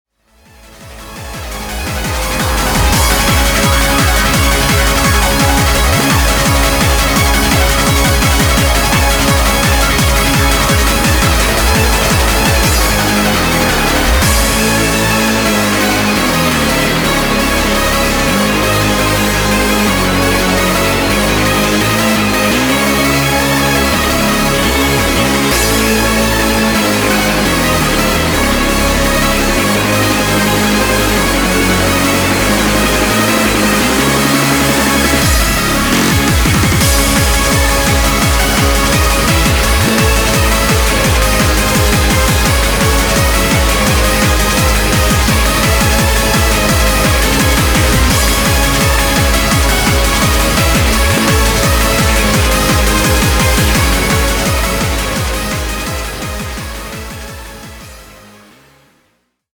Electro、Tech、Hard Style等を収録し、Vocal曲も2曲収録しております。